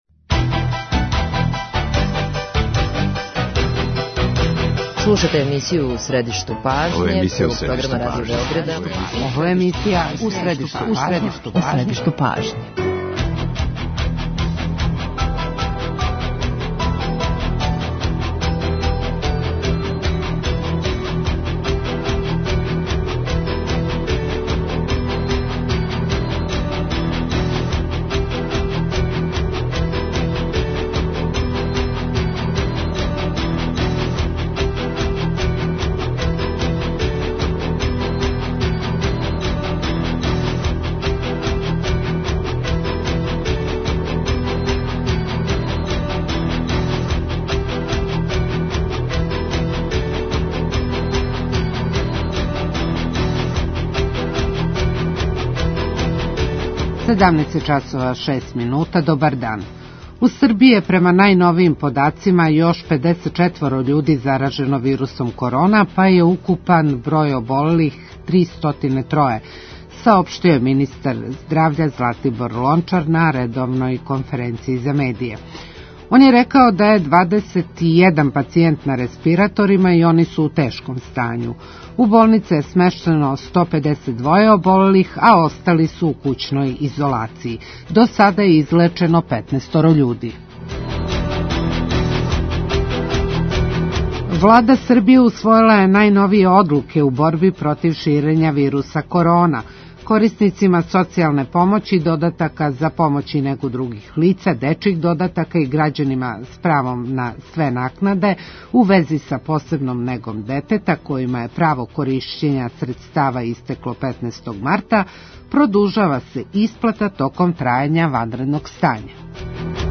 Саговорник емисије је Владислав Јовановић, дипломата, сведок и савременик збивања 1999 године.